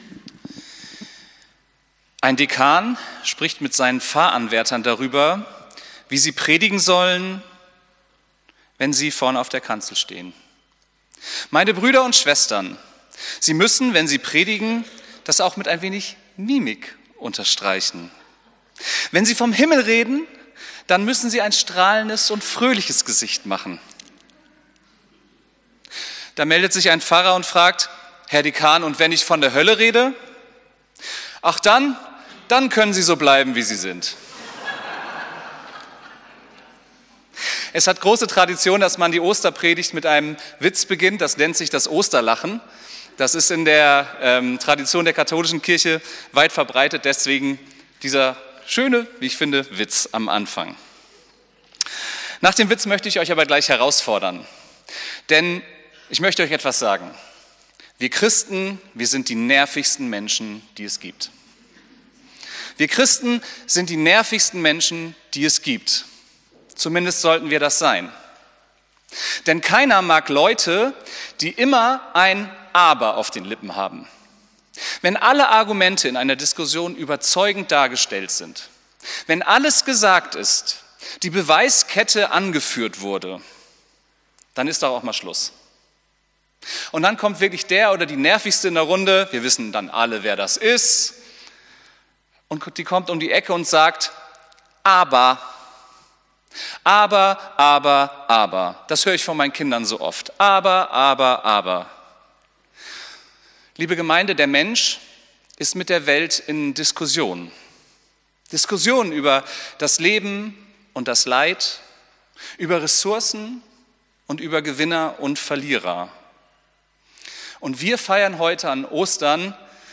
Predigt vom 20.04.2025